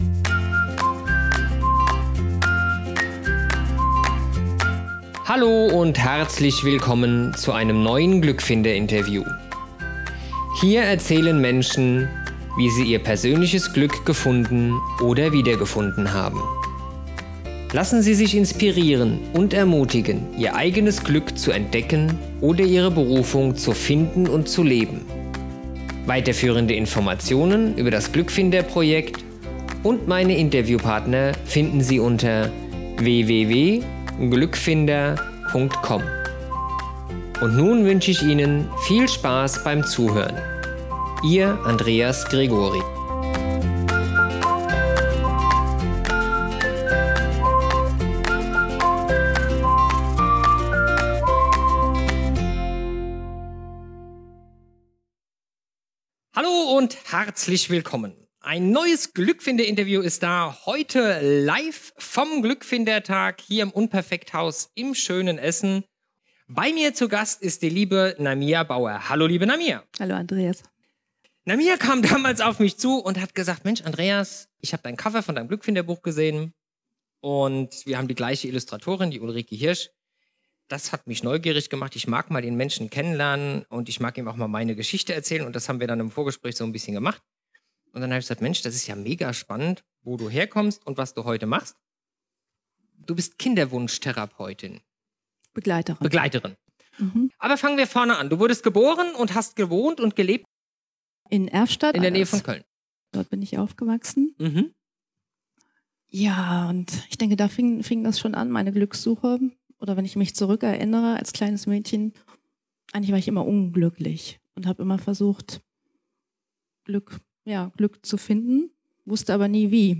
Am Glückfinder Tag im Januar 2018